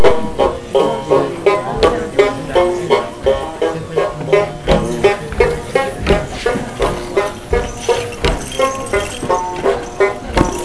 Musique Thai
Musique Thai.WAV